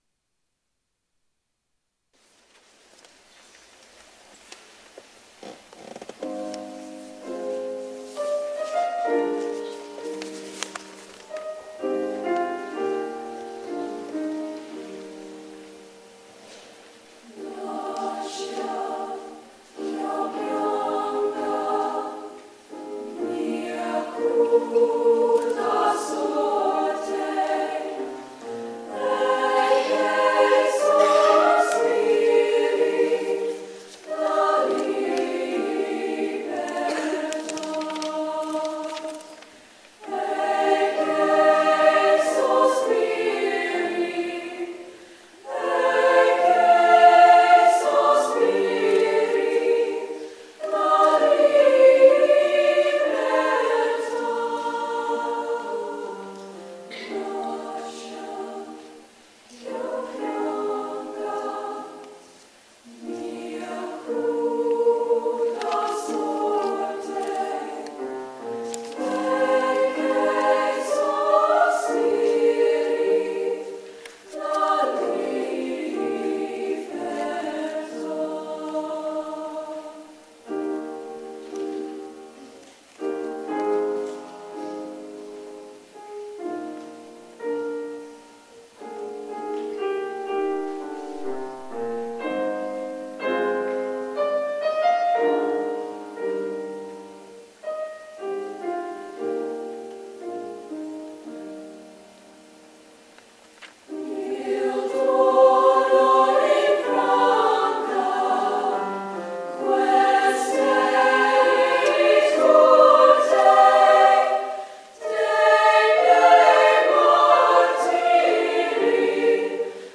2 voix de femmes